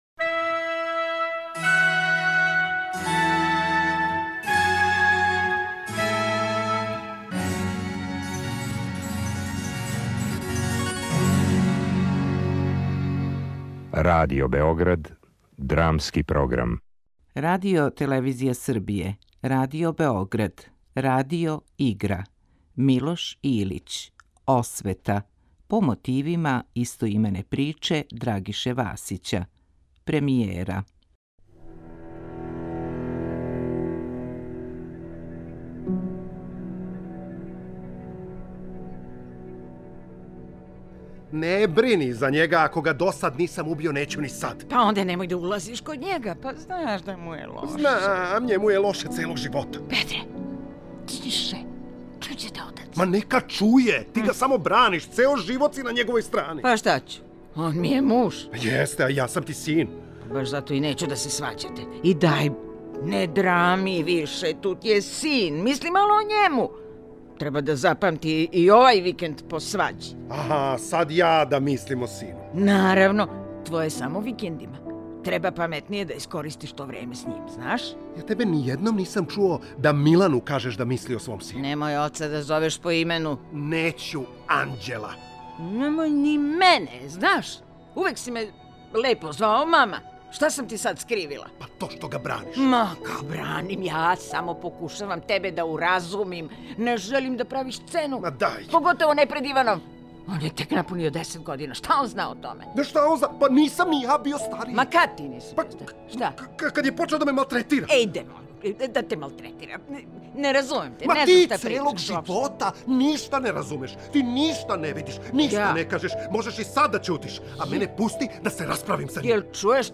Радио-игра